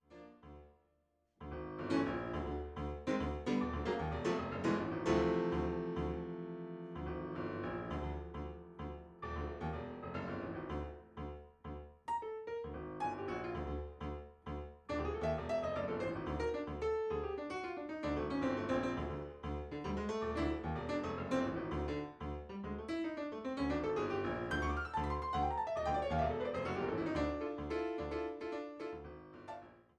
Piano
Andante